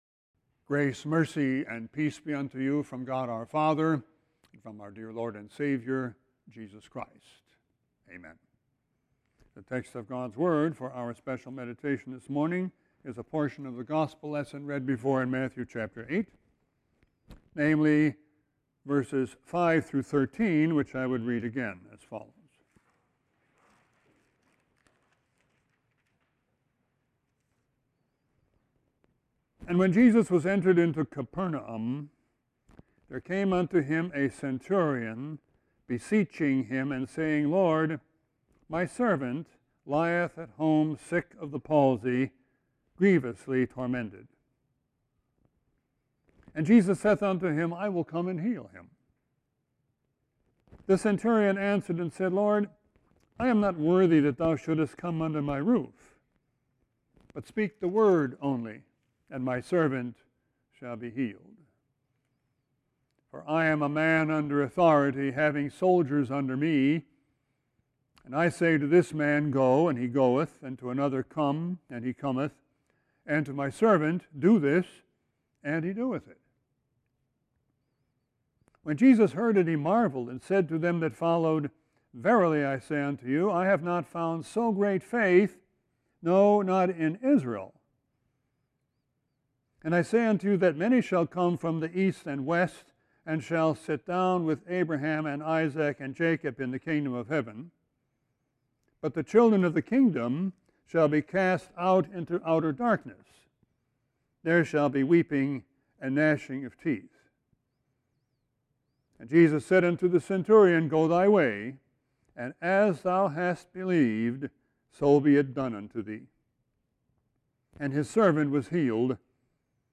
Sermon 1-27-19.mp3